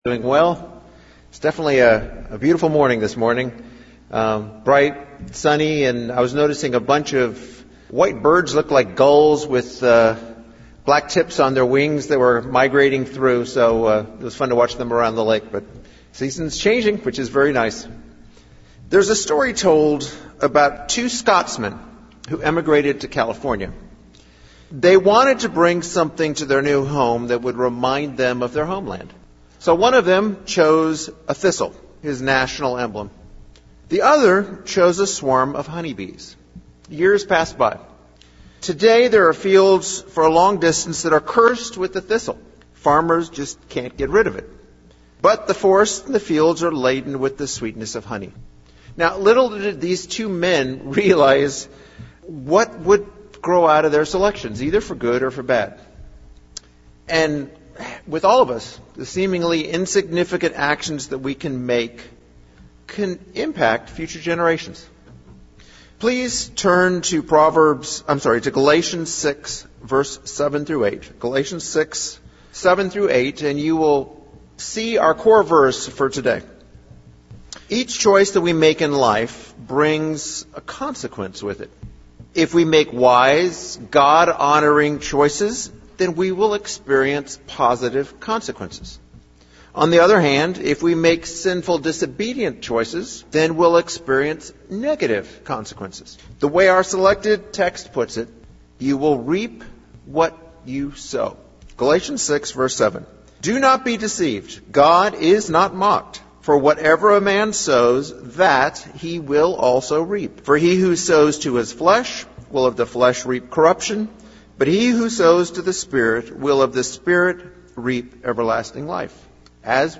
Good sermon looking at the concept of sowing and reaping and its use in scripture. We reap from God, what we sow towards others.